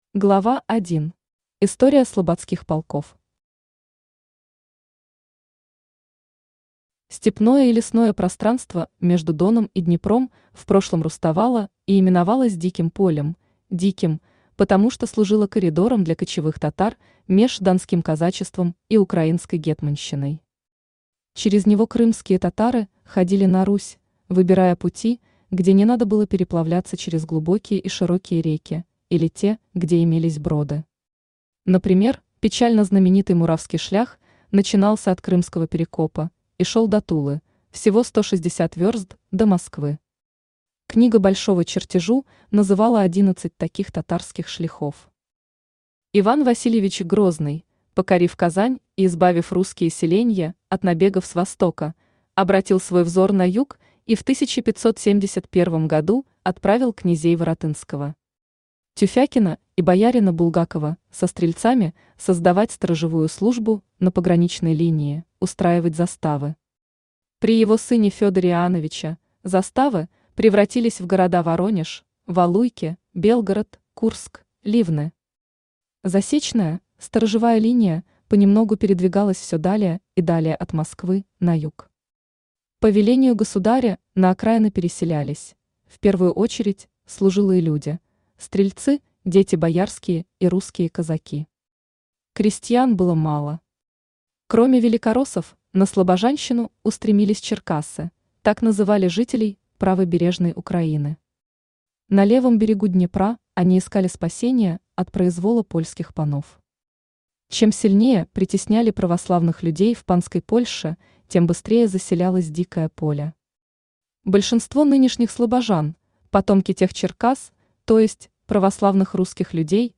Аудиокнига Стрекозка Горгона Уланы в Умани | Библиотека аудиокниг
Aудиокнига Стрекозка Горгона Уланы в Умани Автор Елена Гостева Читает аудиокнигу Авточтец ЛитРес.